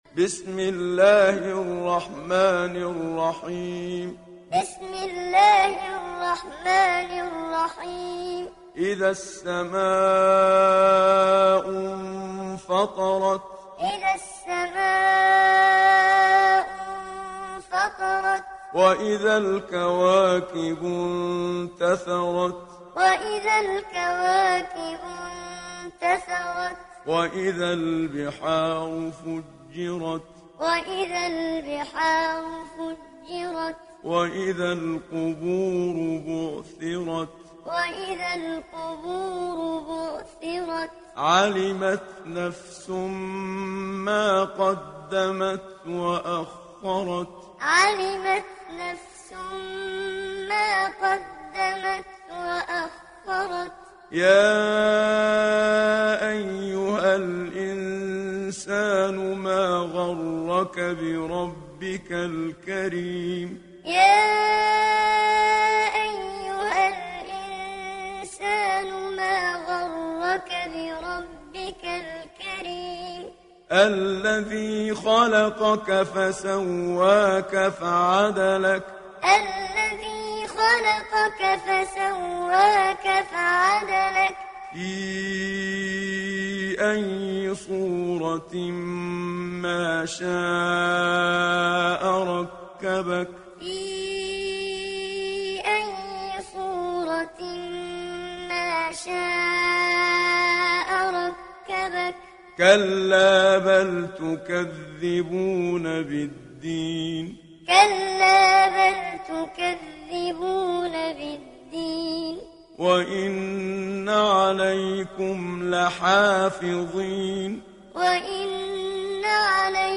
Hafs an Asim
Muallim